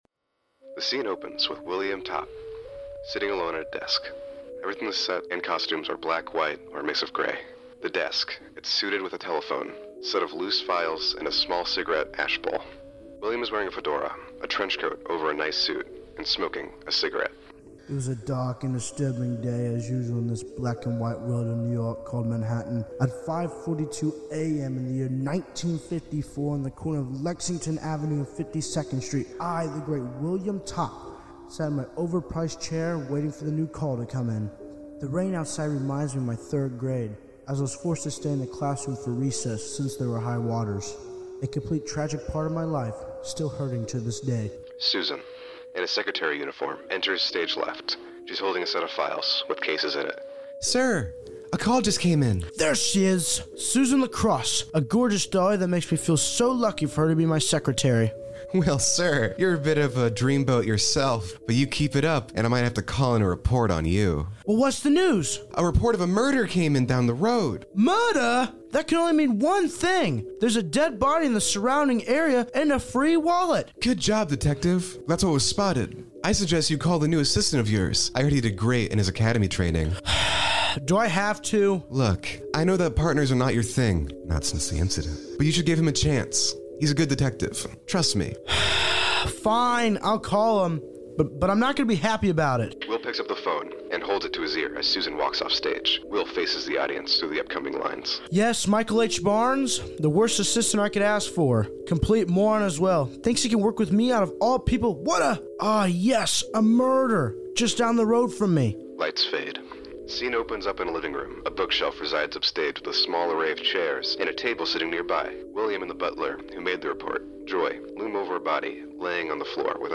Noir-Ished by Mystery | A Radio Drama